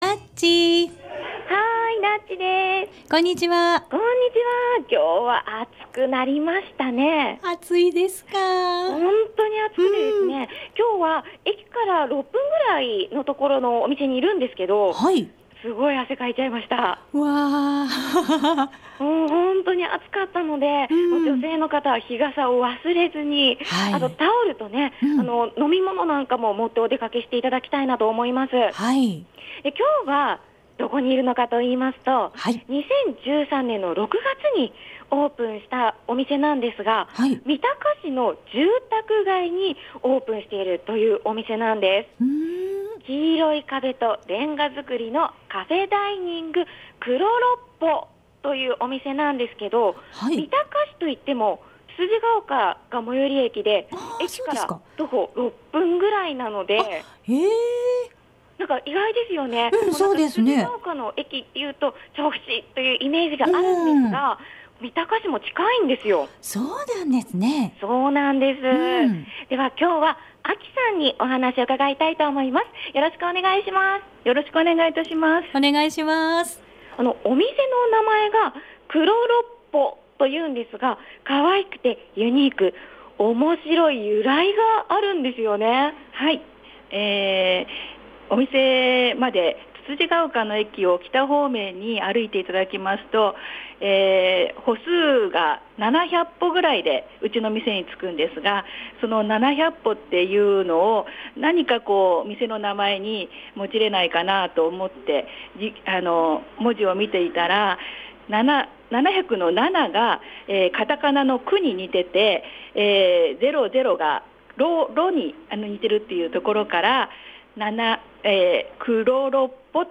午後のカフェテラス 街角レポート
クロロッポ 今日のレポートはつつじヶ丘から徒歩６分の カフェダイニング「クロロッポ」に伺いました（＾－＾） たくさんのお客様が見守って下さいました☆ とってもかわいいお店！！